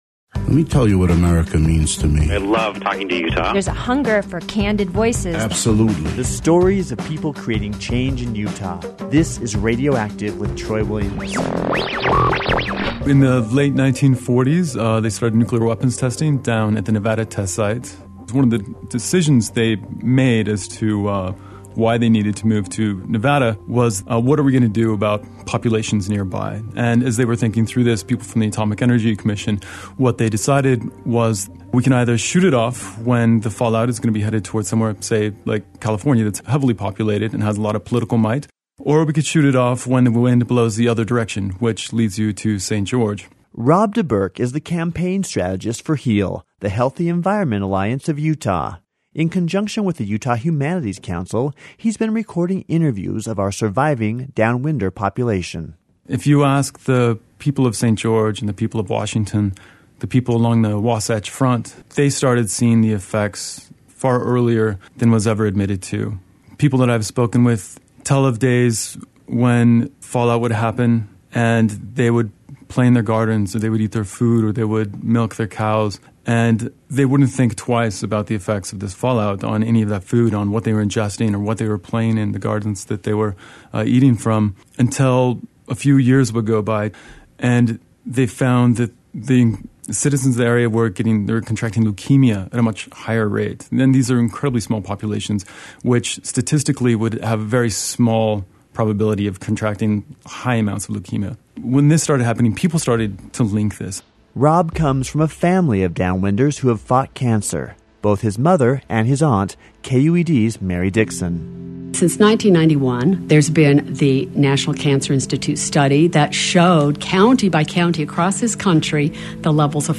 Downwinder Interviews
RadioActive-Downwinder-Interviews-4-.mp3